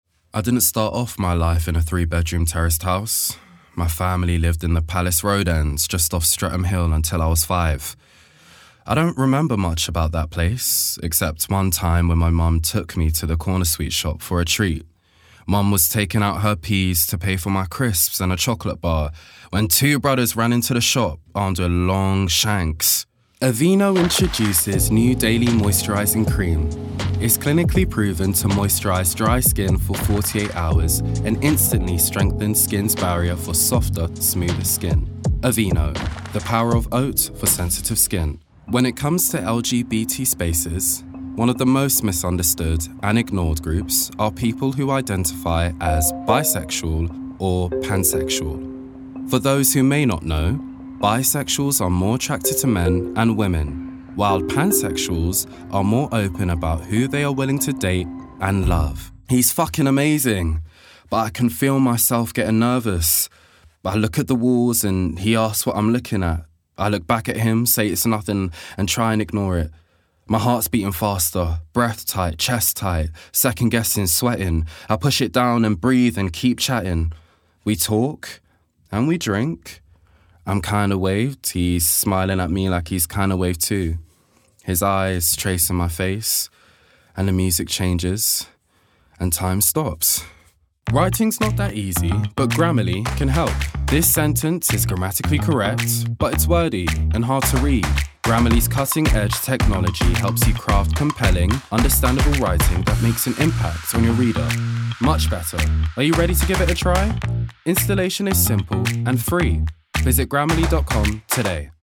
Native voice:
London
Voicereel:
Baritone